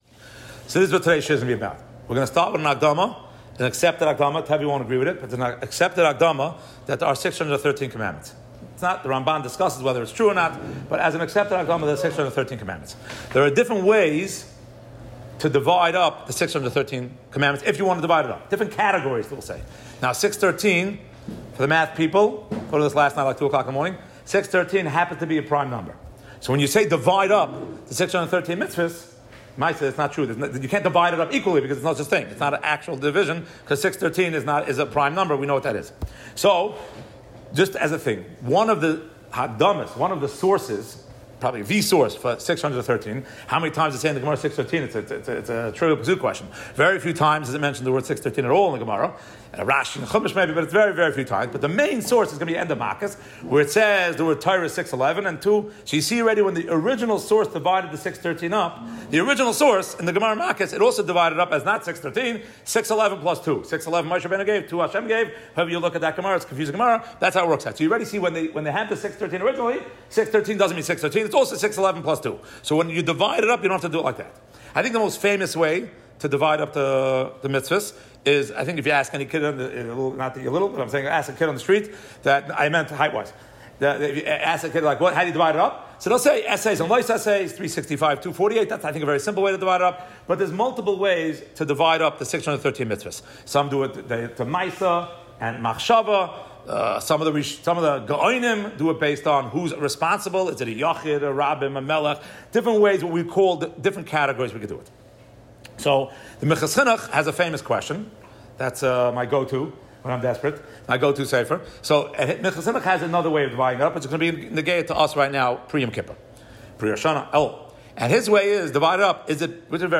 Shaarei Halacha, Monsey. Is it a sin against man (the accursed) or against Hashem (a victimless crime)? The difference is how one is supposed to repent.